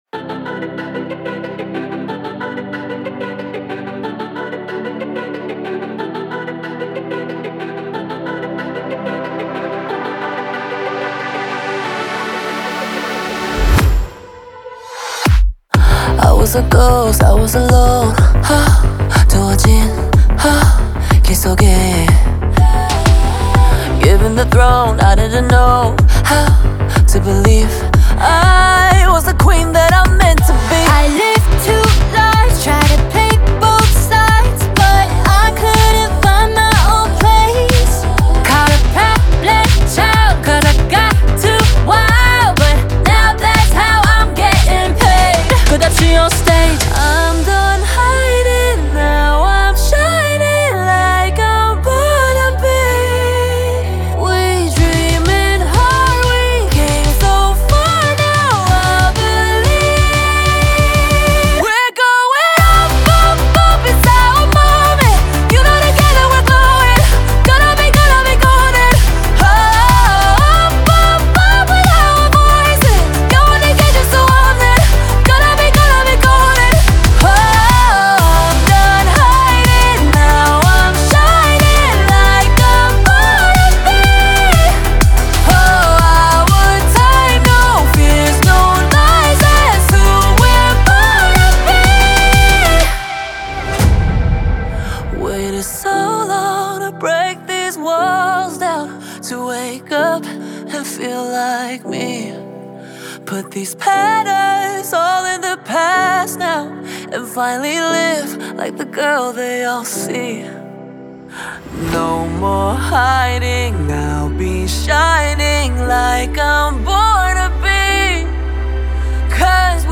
Jubiliation has once again filled the air a K-Pop hit maker